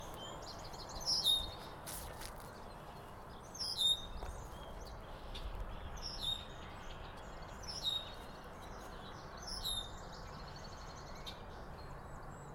You can hear one bird very prominently, but others further in the distance fill out the background.
loudest-bird-chirp.mp3